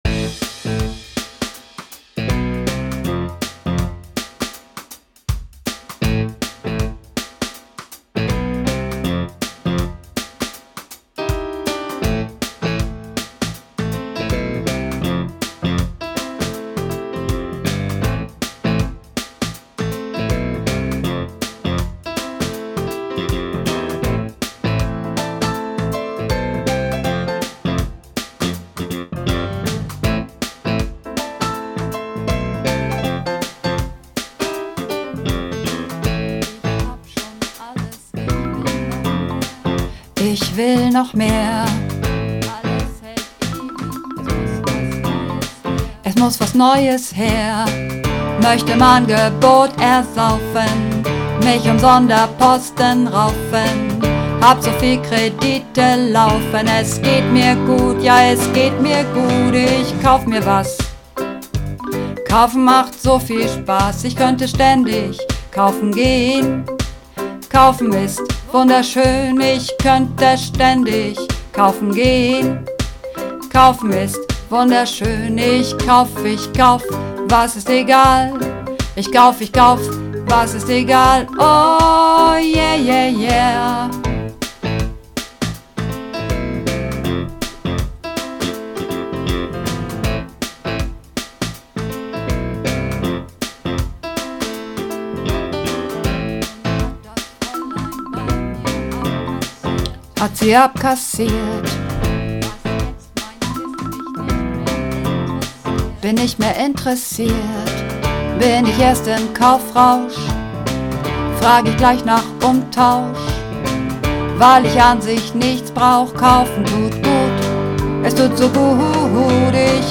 Übungsaufnahmen - Kaufen
Kaufen__2_Bass.mp3